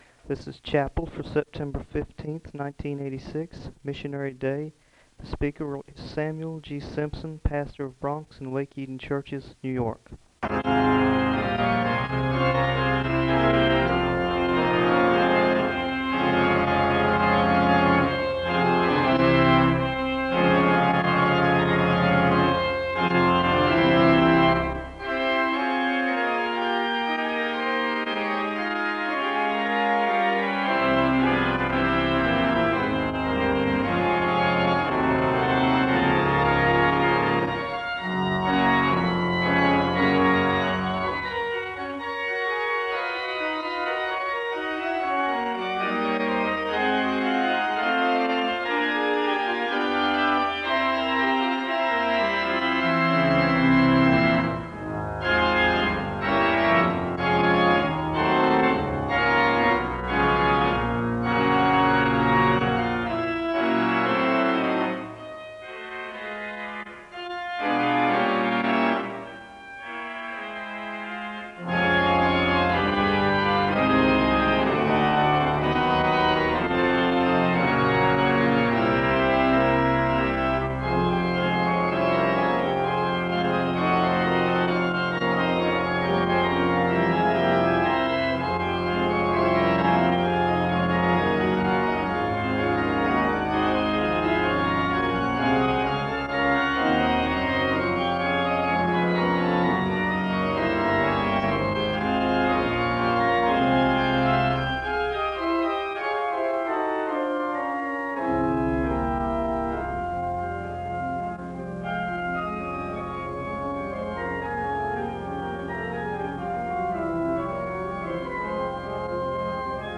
The service begins with organ music (0:00-9:14). The choir sings a song of worship (9:15-12:02). There is a moment of prayer (12:03-13:43).
The choir sings an anthem (15:55-18:16).
The service closes with a word of prayer (57:25-58:26).